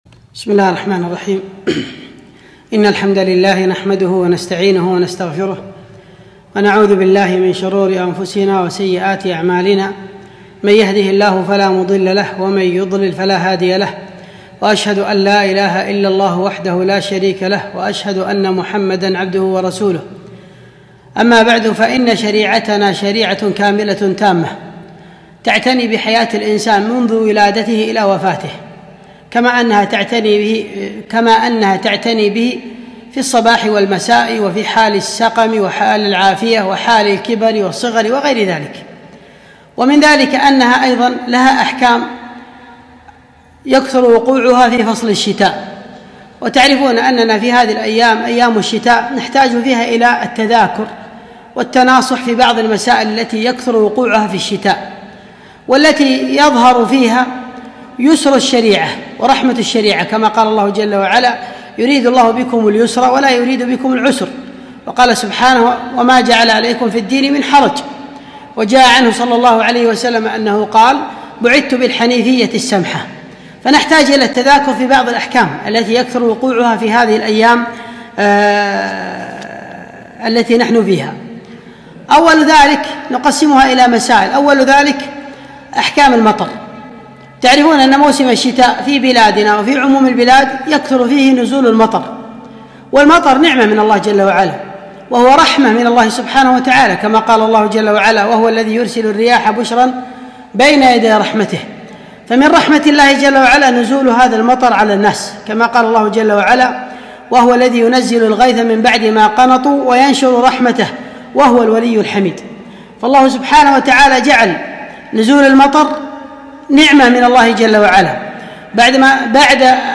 محاضرة - أحكام الشتاء